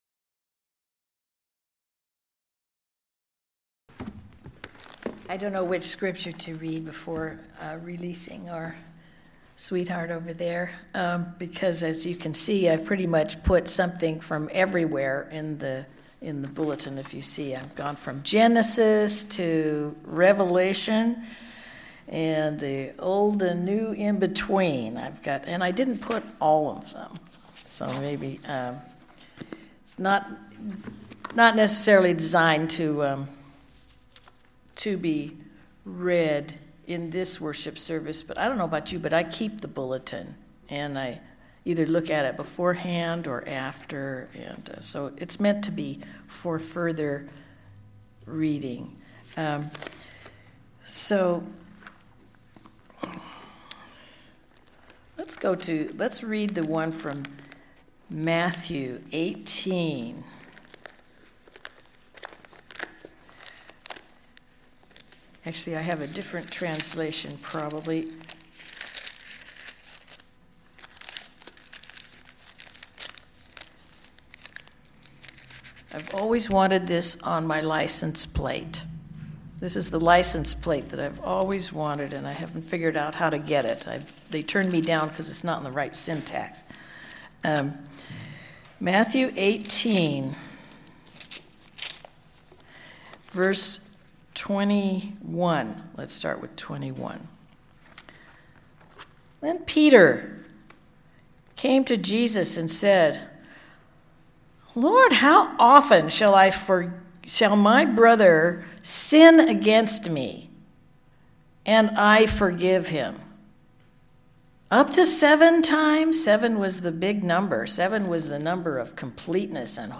Listen to the most recent message, “Favorite 7’s!” from Sunday worship at Berkeley Friends Church.